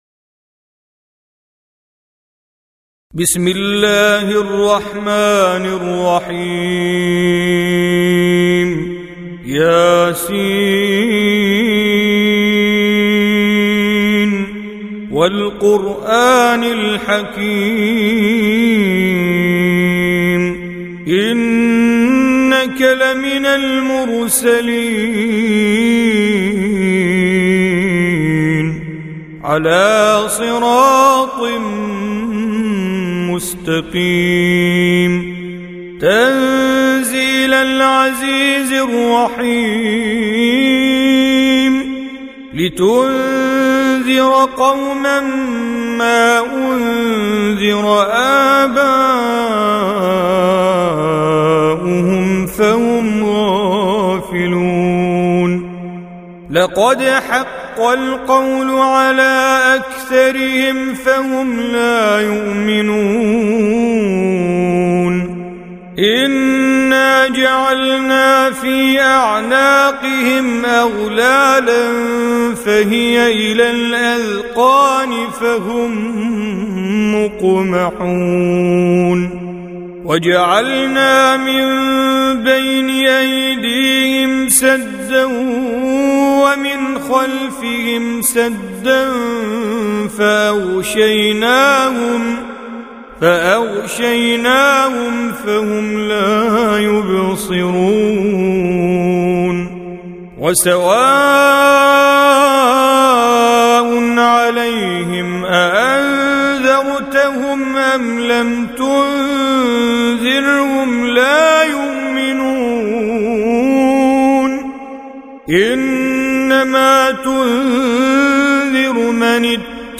36. Surah Y�S�n. سورة يس Audio Quran Tajweed Recitation
Surah Repeating تكرار السورة Download Surah حمّل السورة Reciting Mujawwadah Audio for 36.